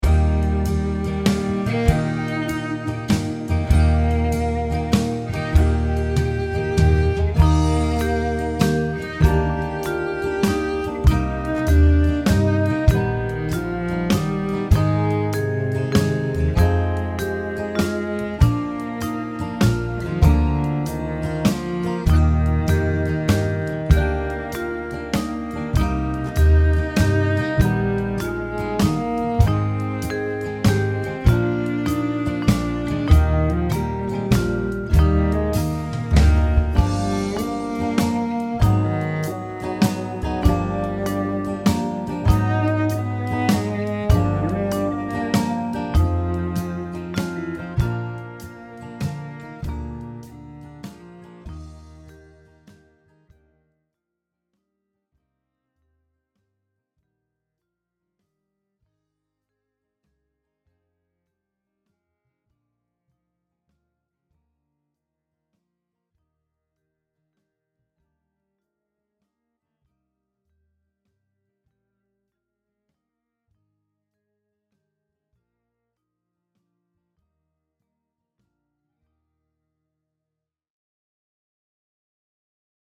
Maza dziesmiņa Play-along.